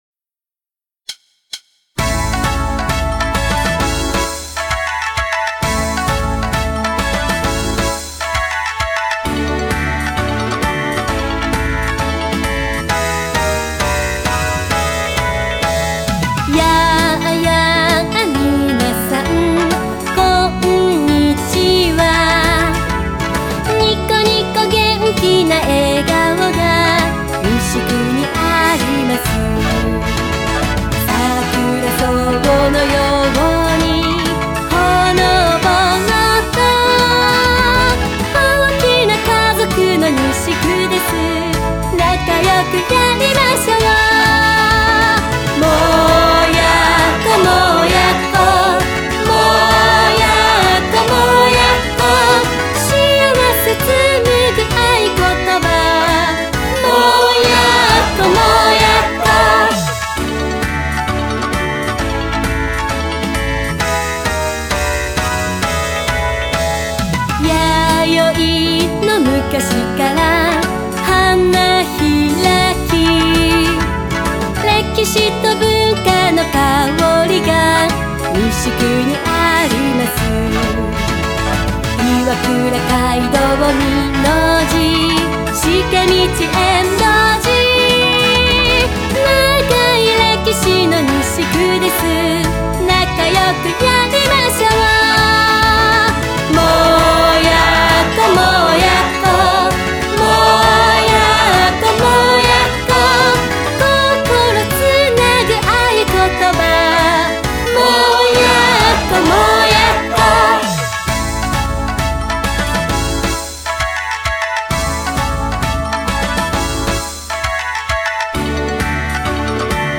もーやっこのうたダウンロード もーやっこのうた （WMA 3.8 MB） みんなで一緒にうたいましょう♪右クリックでダウンロードしてね♪※音楽のみの配信です。